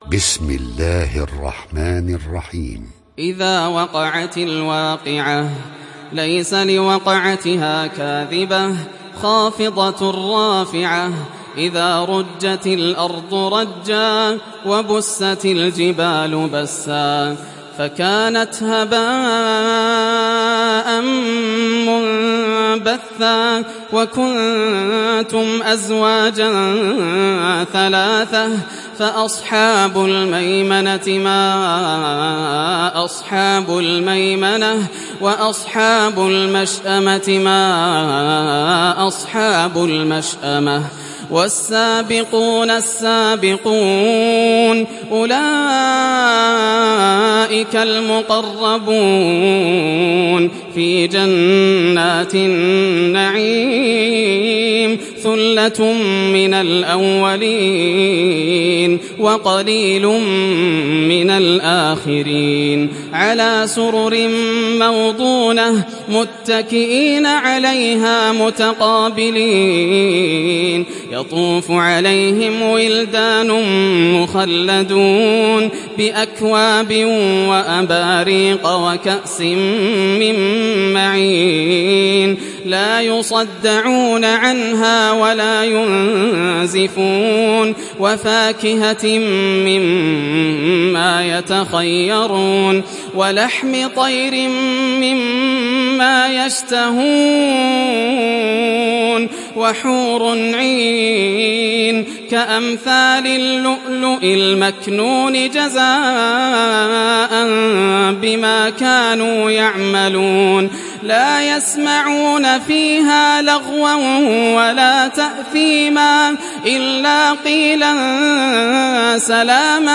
Surat Al Waqiah Download mp3 Yasser Al Dosari Riwayat Hafs dari Asim, Download Quran dan mendengarkan mp3 tautan langsung penuh